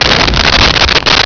Sfx Crash Jungle A
sfx_crash_jungle_a.wav